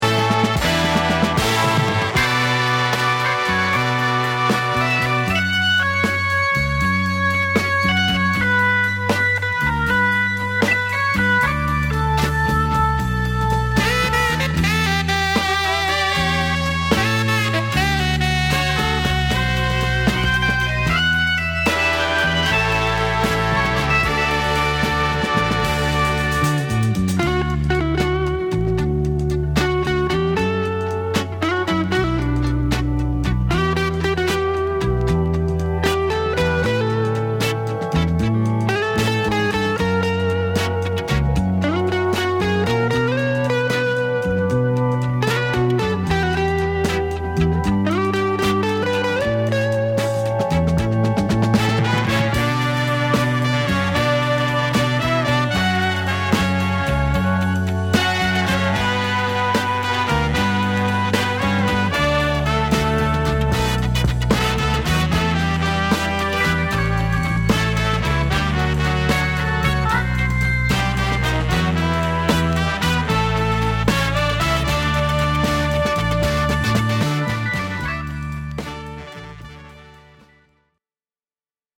和製レアグルーヴ／ドラムブレイク／インスト集 試聴